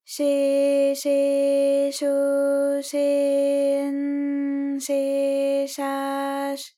ALYS-DB-001-JPN - First Japanese UTAU vocal library of ALYS.
she_she_sho_she_n_she_sha_sh.wav